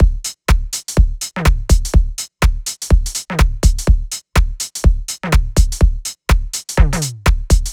Index of /99Sounds Music Loops/Drum Loops/Dance